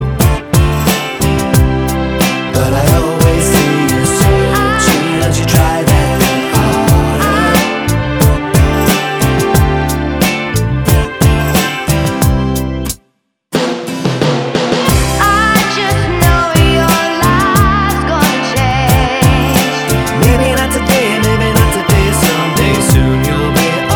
Duet Version Pop (2000s) 3:32 Buy £1.50